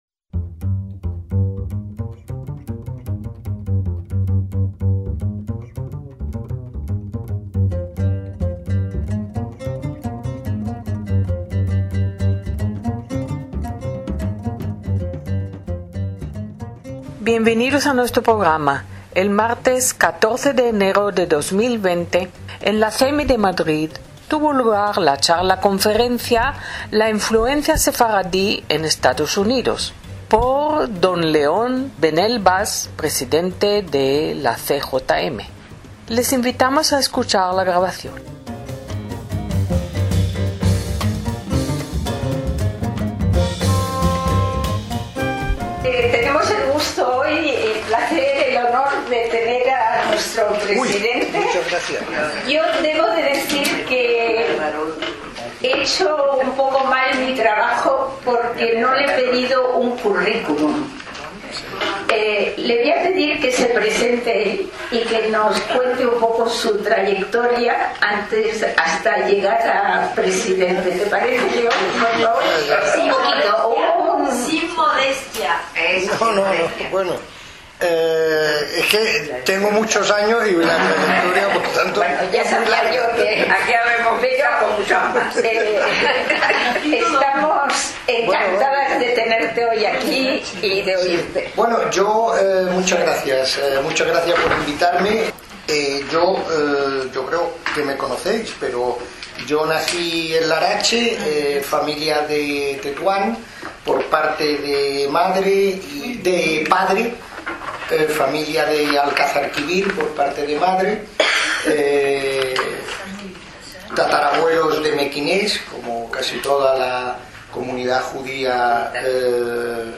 ACTOS EN DIRECTO